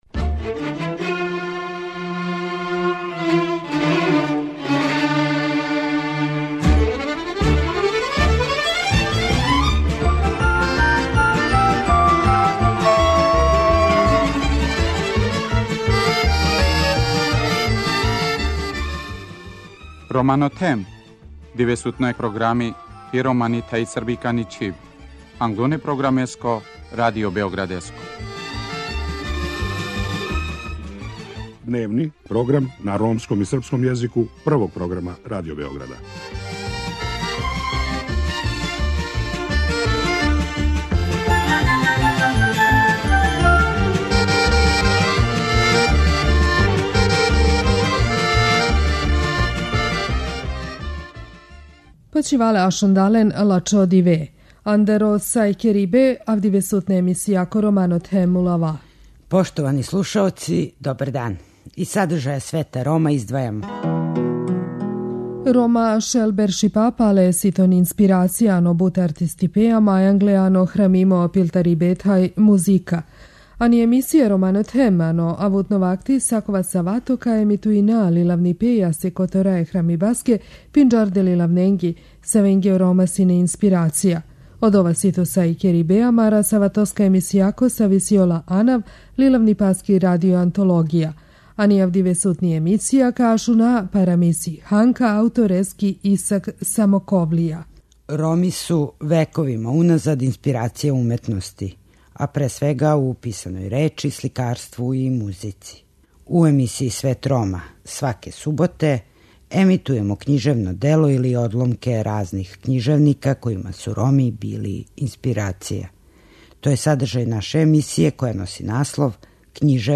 У данашњем издању слушамо приповетку "Ханка" аутора Исака Самоковлије.